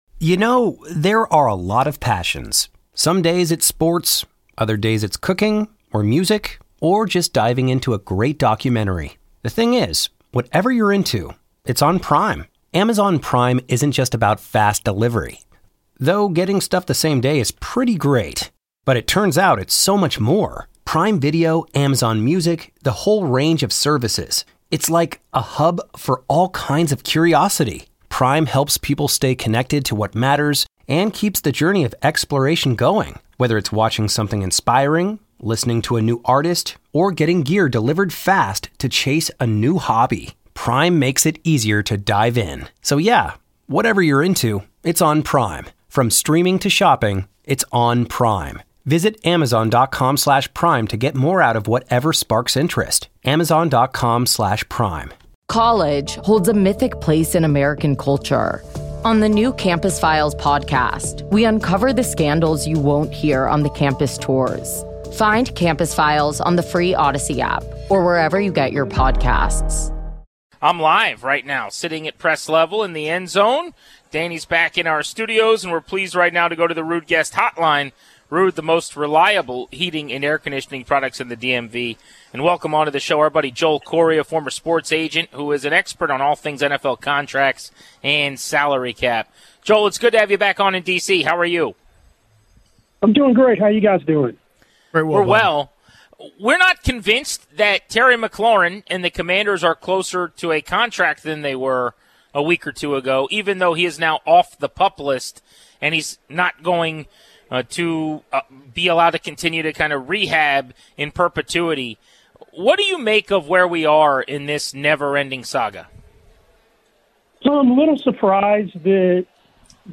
Best Interviews on 106.7 The Fan/Team 980: August 18-22, 2025